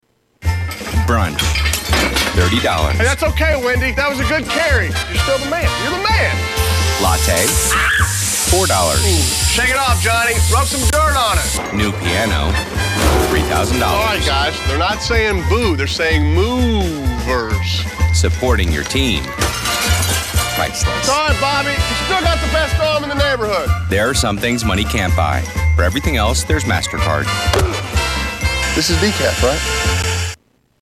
Tags: Media MasterCard Advertisement Commercial MasterCard Clips